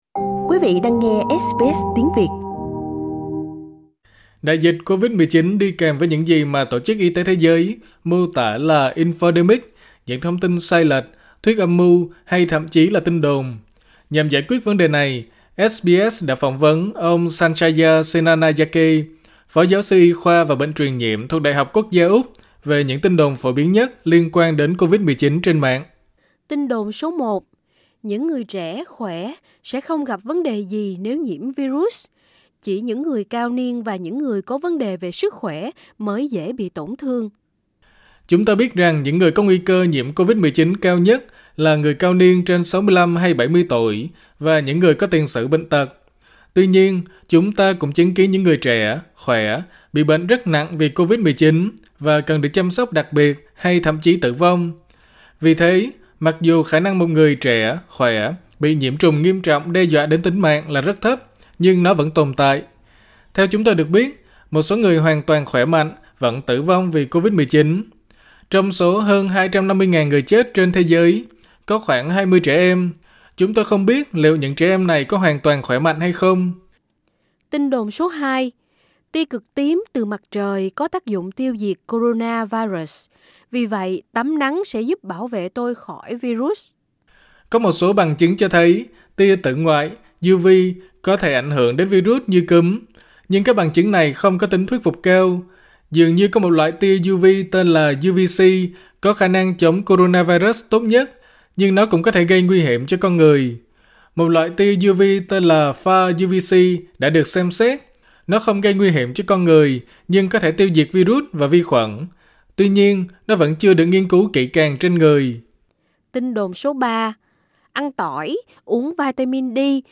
SBS phỏng vấn